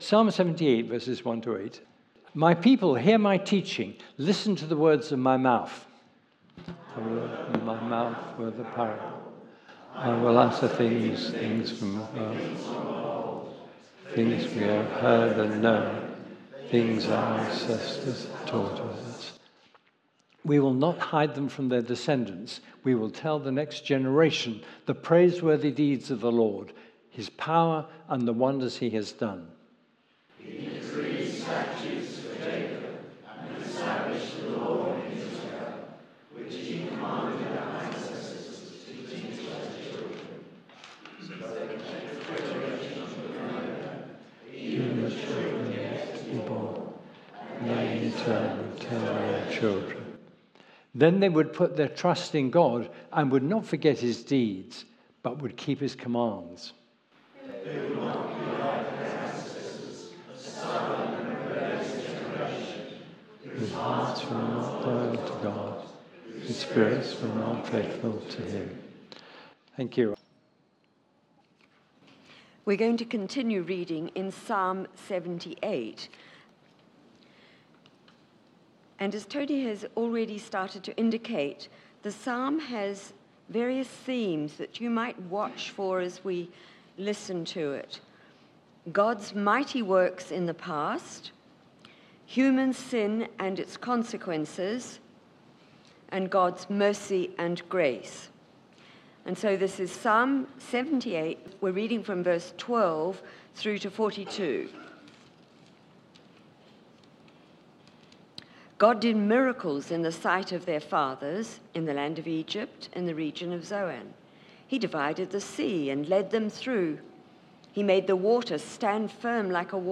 Sunday Service
Theme: Sermon